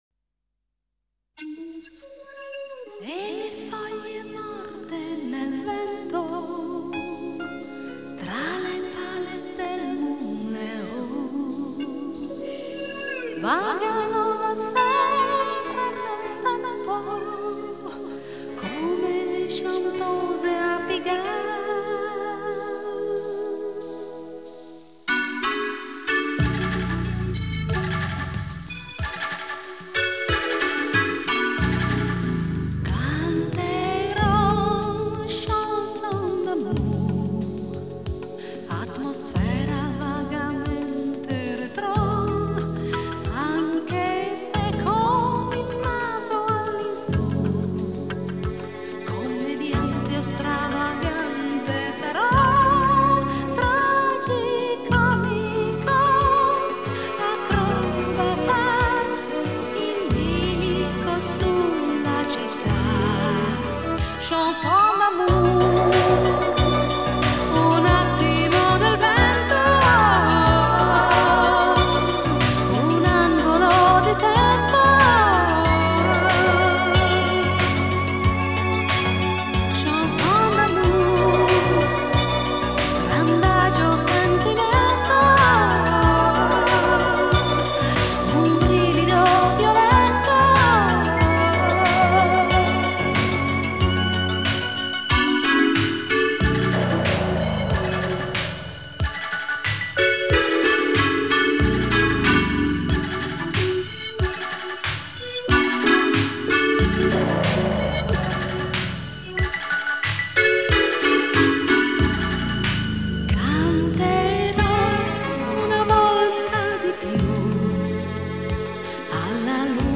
Genre: Retro Pop